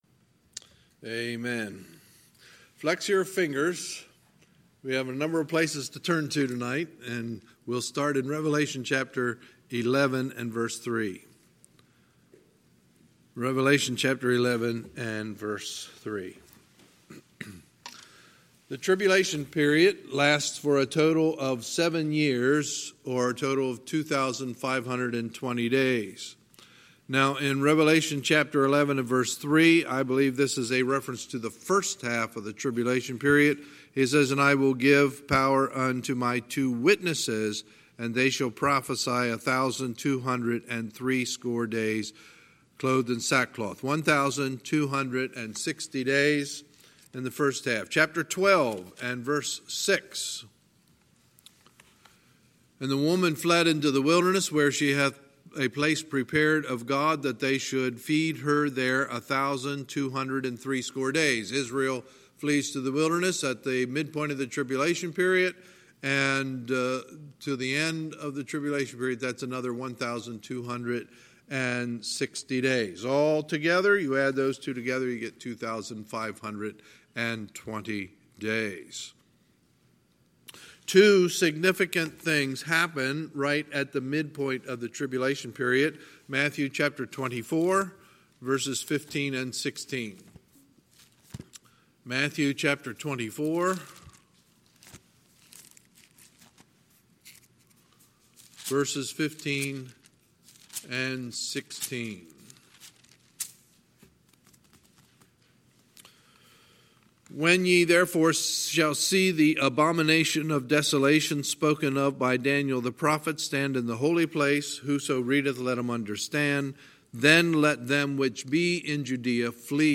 Sunday, July 28, 2019 – Sunday Evening Service